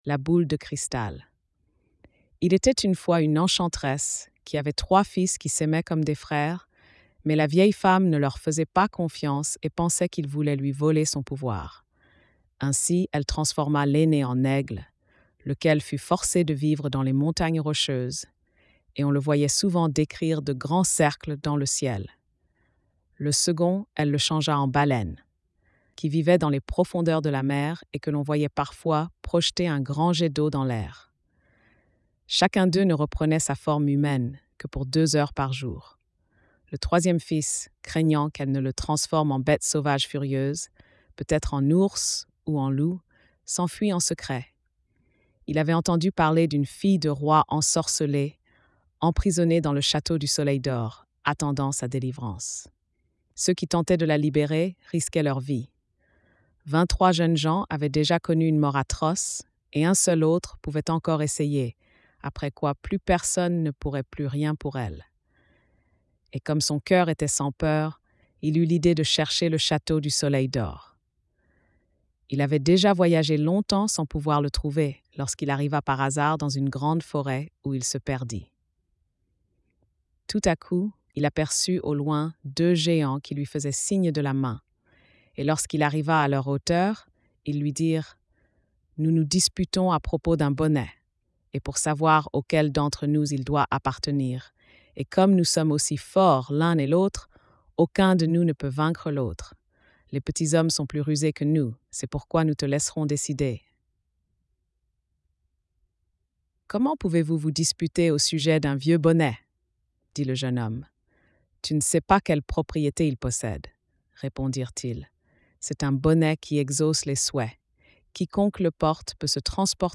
Conte de Grimm
🎧 Lecture audio générée par IA